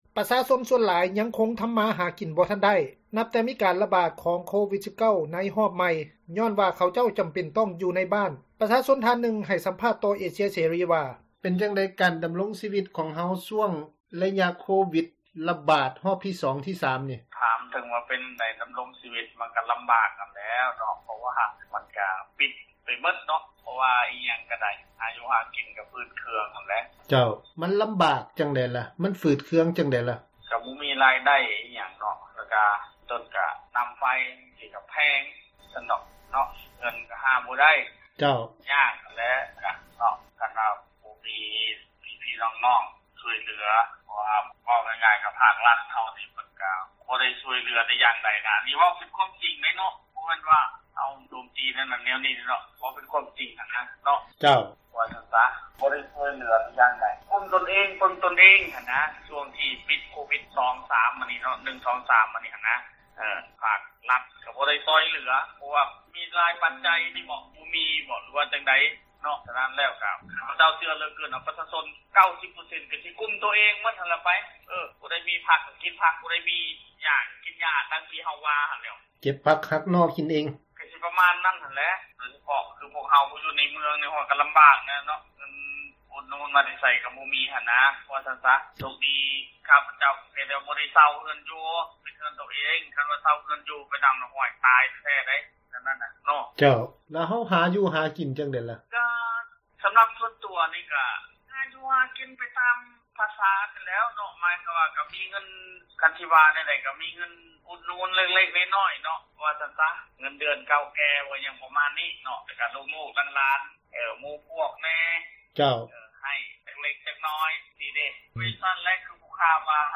ປະຊາຊົນທ່ານນຶ່ງ ໃຫ້ສຳພາດ ຕໍ່ເອເຊັຽເສຣີ ວ່າ: